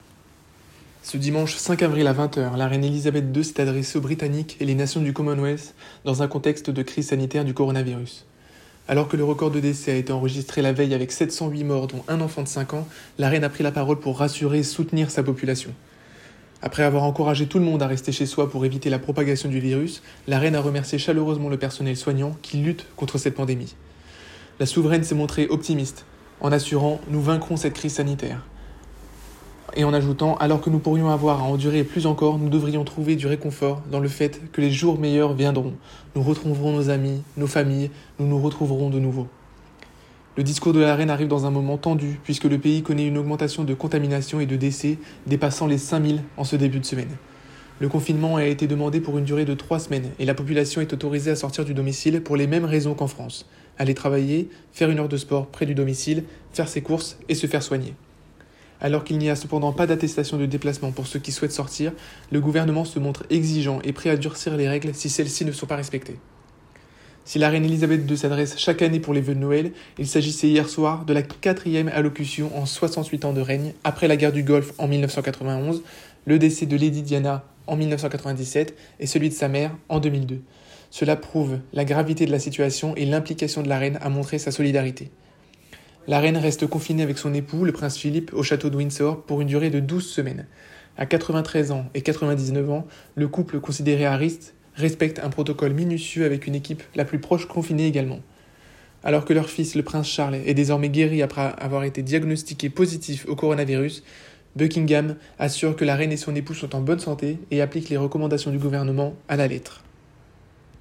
La Reine prend la parole (930.97 Ko)